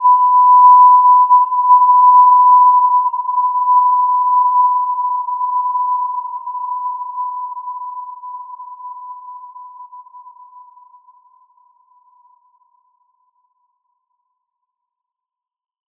Gentle-Metallic-4-B5-p.wav